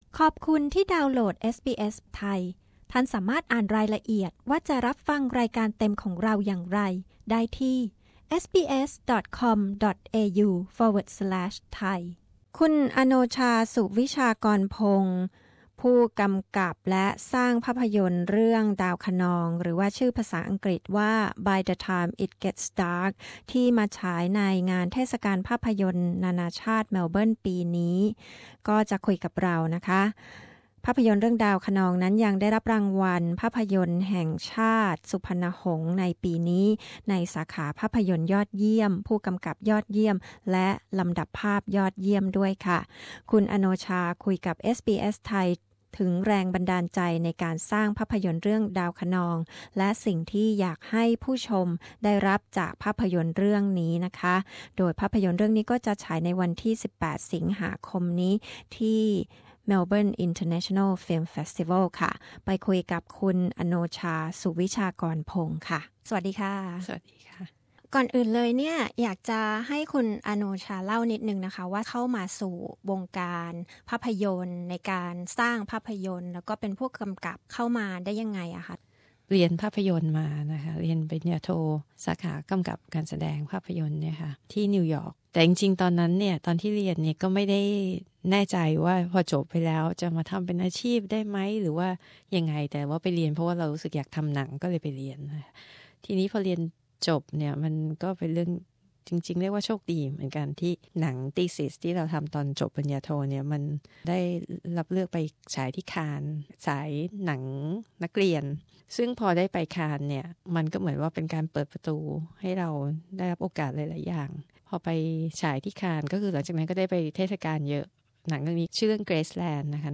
คุณอโนชา สุวิชากรพงศ์ ผู้กำกับภาพยนตร์ เรื่องดาวคะนอง ซึ่งได้รางวัลสุพรรณหงส์ ภาพยนตร์ยอดเยี่ยม และผู้กำกับยอดเยี่ยมปีนี้ เล่าถึงแรงบันดาลใจจากเหตุการณ์ 6 ตุลา 2519 ที่นำมาสร้าง ดาวคะนอง และสิ่งที่อยากให้ผู้ชมได้รับจากภาพยนตร์เรื่องนี้